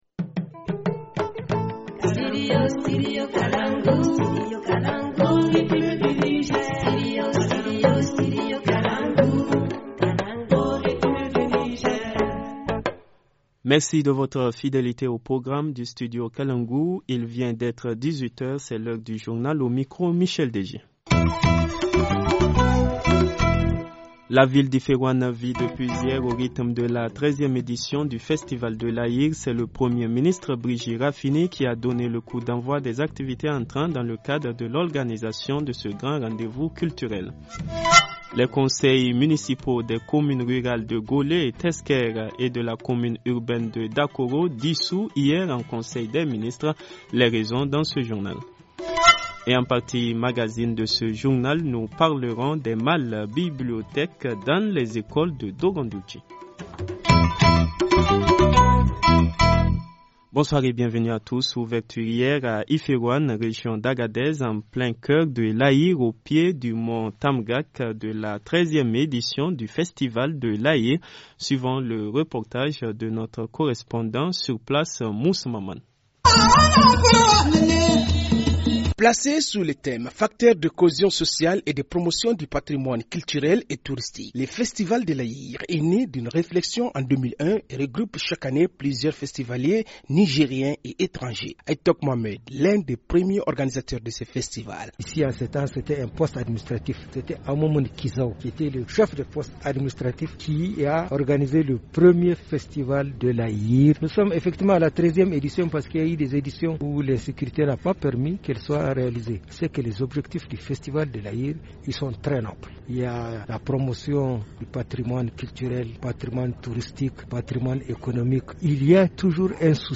Journal du 17 février 2018 - Studio Kalangou - Au rythme du Niger